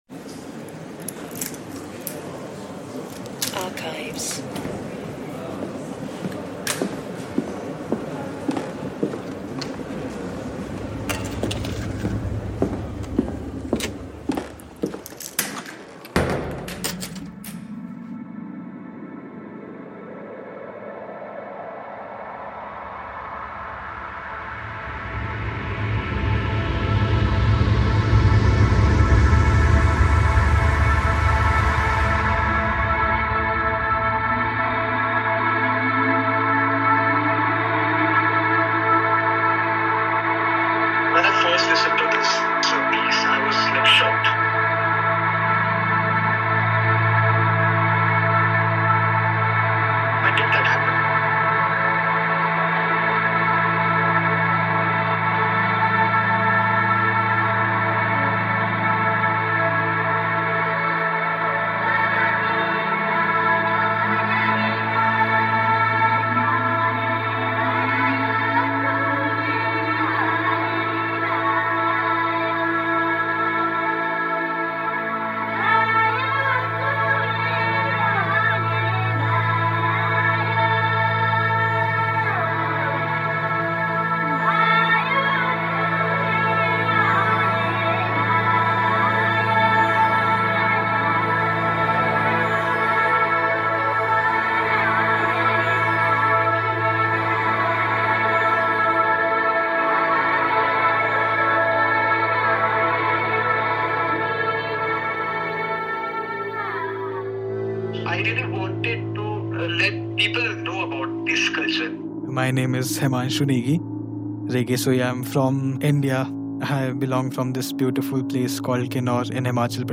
this piece is a meeting of old and new, bringing a sonic archive to life from the Western Himalayas.
a field recording of women singing in the early 1980s
Through his voice a living thread emerges to celebrate a rich and dynamic culture - both ancient and modern.
The original field recording inspired various textures and loops created to take the listener on a journey from the dusty archives of a museum in Oxford to the vast Himalayas, evoking landscape and opening space for exploring how we deal with the past in the present - to inspire the future.